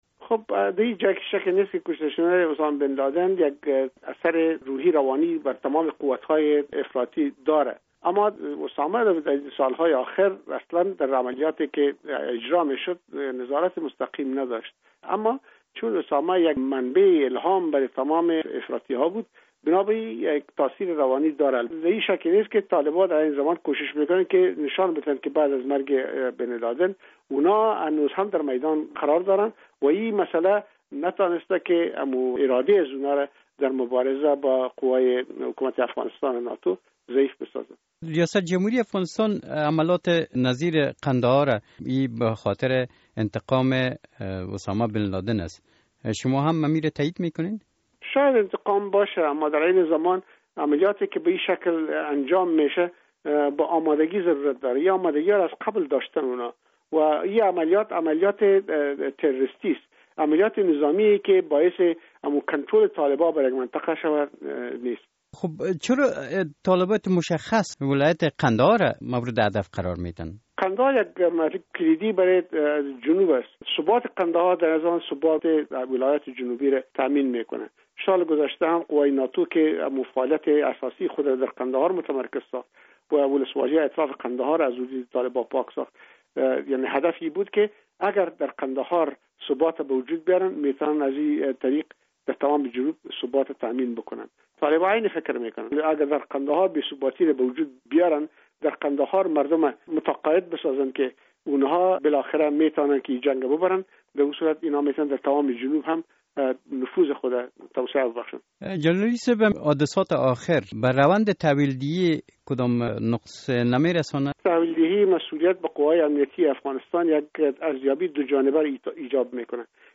مصاحبه با علی احمد جلالی در مورد اوضاع امنیتی افغانستان